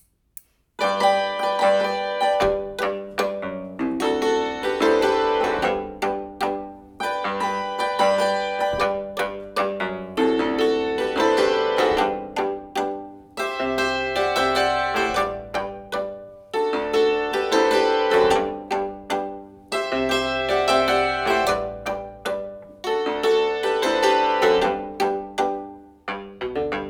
Instrumentalmusik